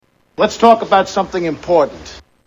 Category: Prank Calls   Right: Personal
Tags: Prank Calls Actor Alec Baldwin Alec Baldwin Audio clips Movies